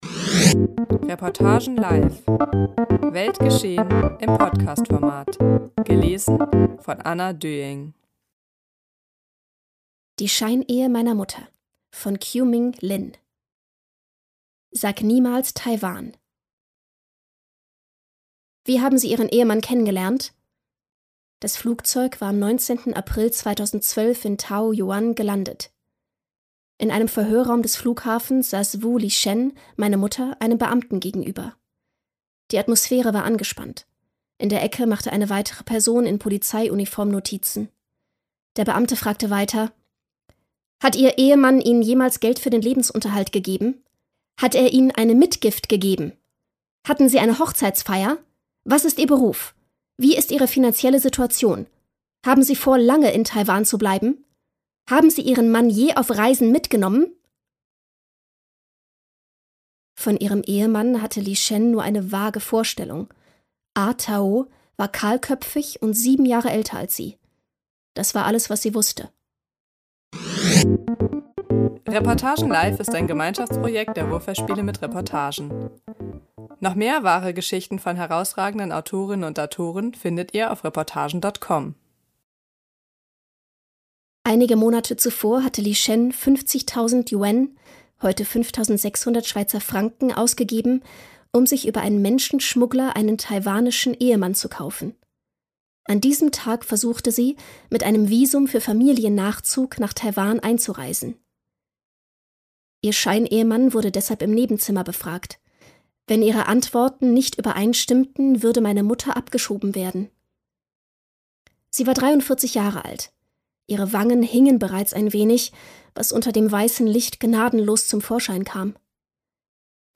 Herausgekommen ist eine sehr persönliche Reportage über eine Mutter-Tochter-Beziehung, in die sich der Taiwan-China-Konflikt tief eingeschrieben hat.